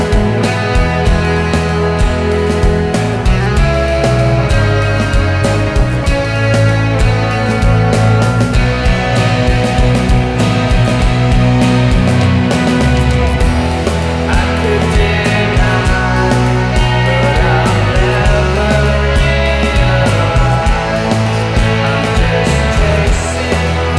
no Backing Vocals Indie / Alternative 4:22 Buy £1.50